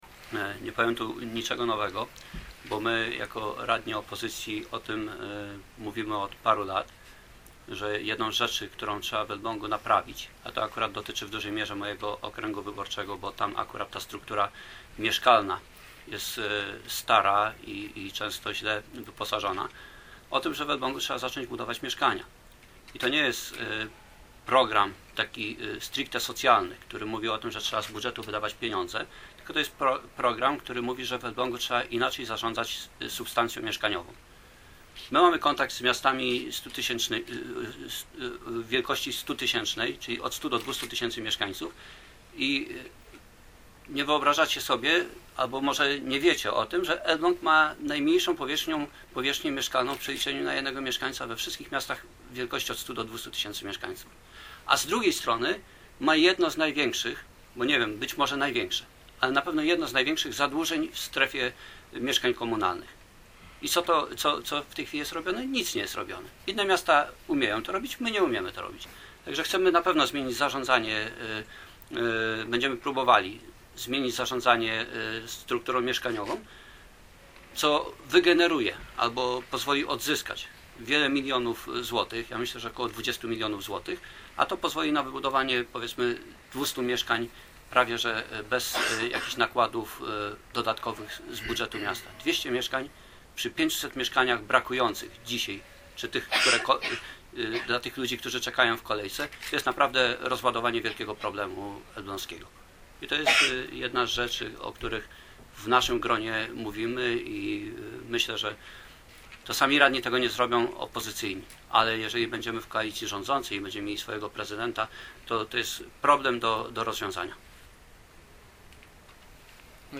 Wczoraj elbl�ska PO przedstawi�a wszystkich swoich kandydatów na radnych. Tu� po prezentacji, liderzy okr�gów spotkali si� dziennikarzami i poruszyli niektóre problemy i inicjatywy, którymi trzeba zaj�� si� w kadencji 2010- 2014.